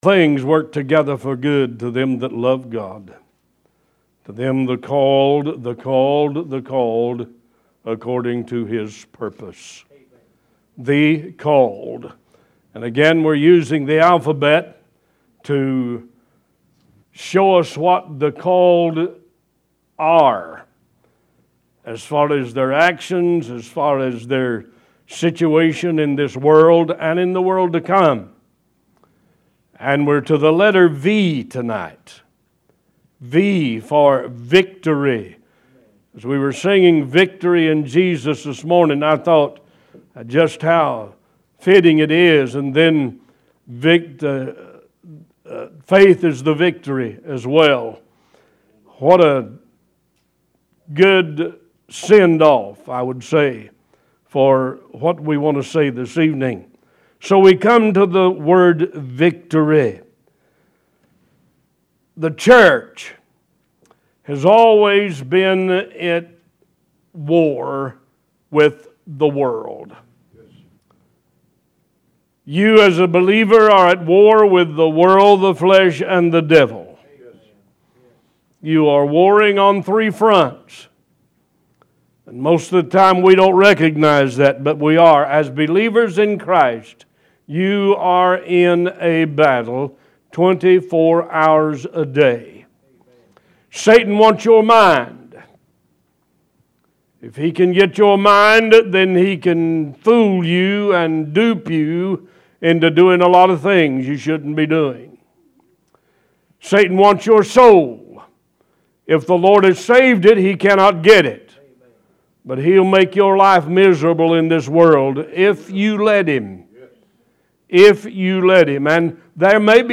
Talk Show Episode
One Voice Talk Show